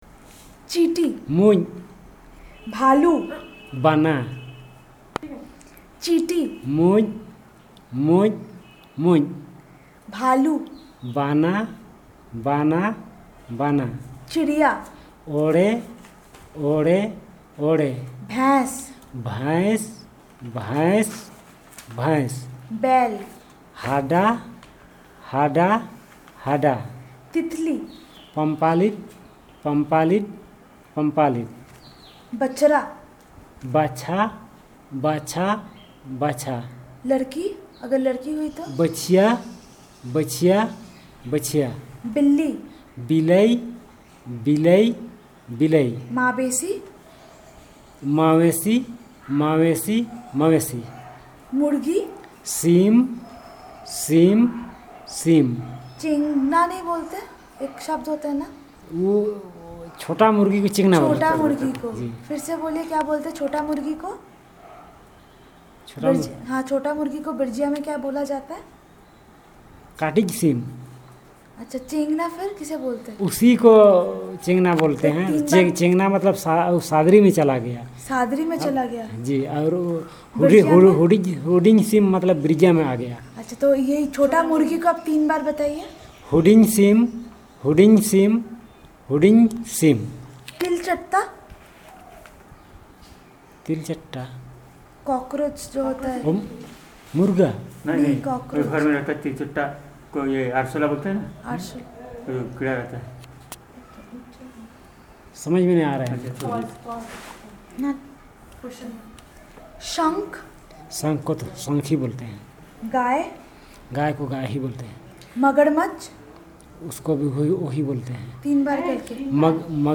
Elicitation of words about insects and animals, and their parts
NotesThis is an elicitation of words about insects and animals - young, old, male, or female - as well as their parts and features where applicable, using Hindi as the language of input from the researcher's side, which the informant then translates to the language of interest